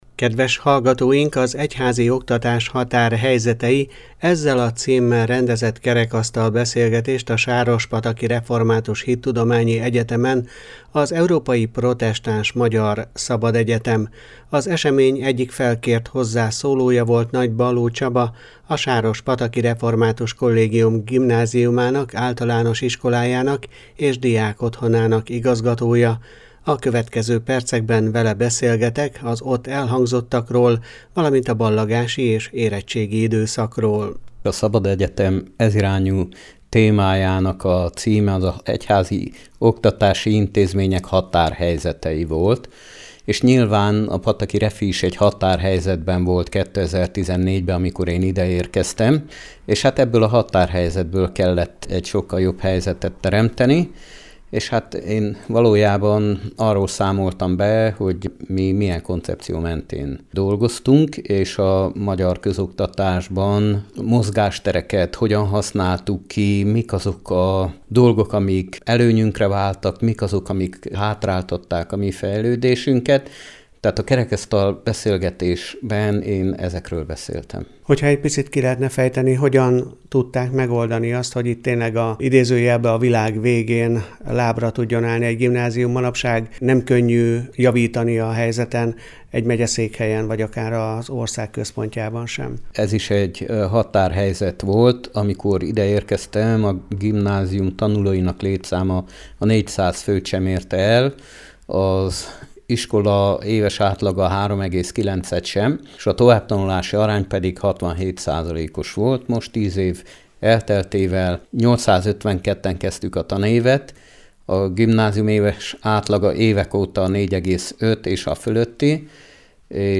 Az egyházi oktatás határhelyzetei – ezzel a címmel rendezett kerekasztal beszélgetést a Sárospataki Református Hittudományi Egyetemen az Európai Protestáns Magyar Szabadegyetem.